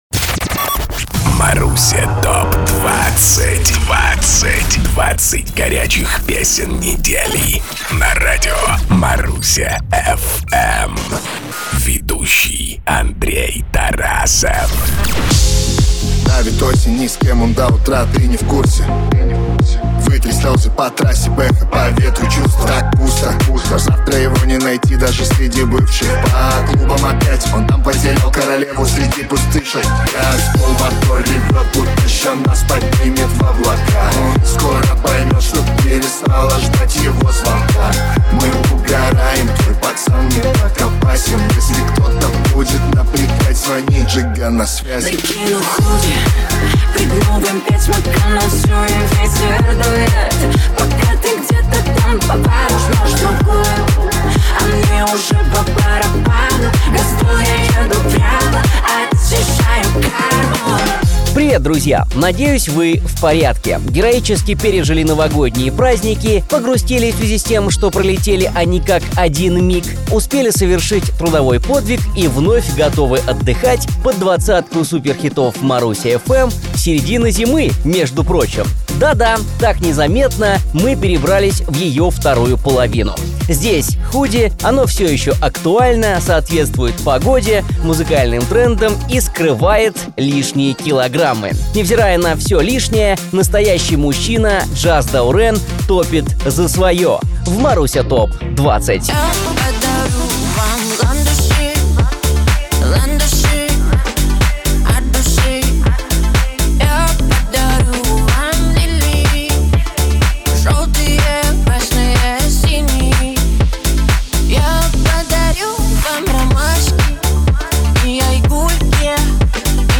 20 хитов за 20 минут!